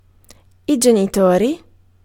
Ääntäminen
IPA : /ˈpɛɹ.ənts/ IPA : /ˈpæɹənts/